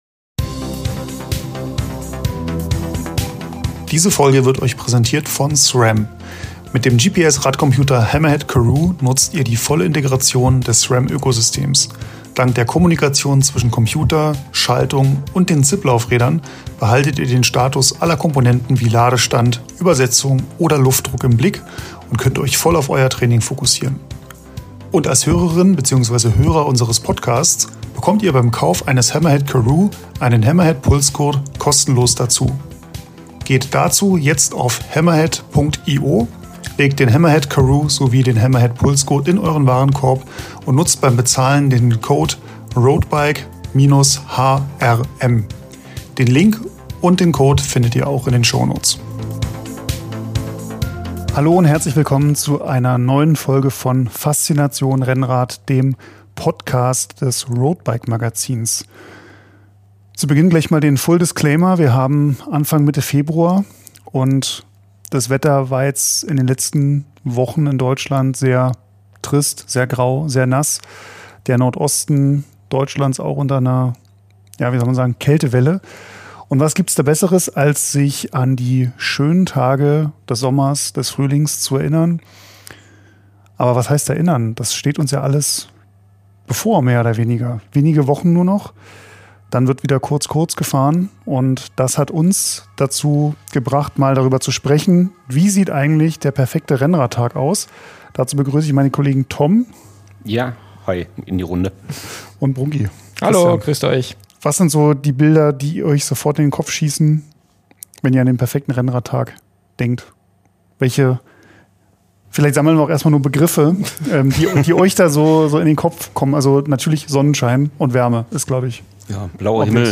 Florian Lipowitz im Exklusiv-Interview